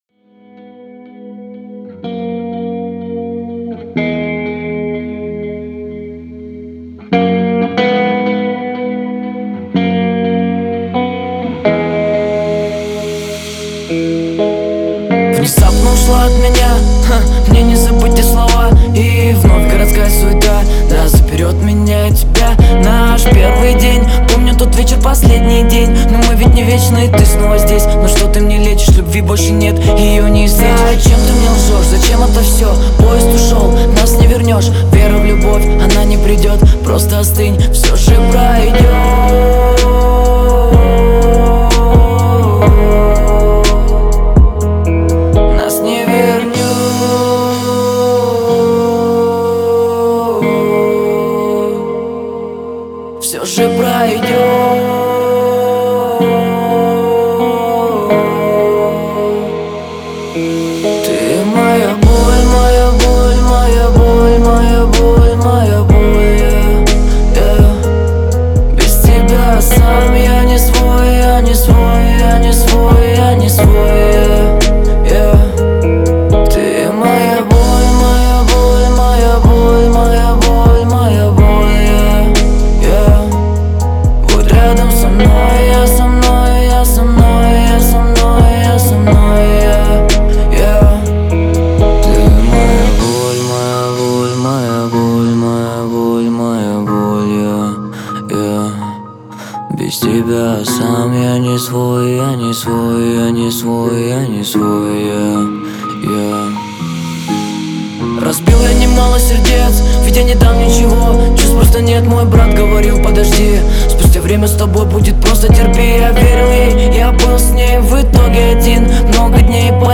мощное сочетание поп-рока и эмоциональной лирики